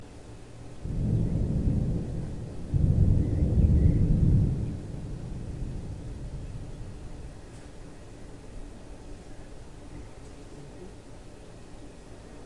打雷和下雨 " 好的打雷和下雨
描述：雷声和雨声你可以用在任何你喜欢的地方
标签： 雷风暴 风暴 暴雨
声道立体声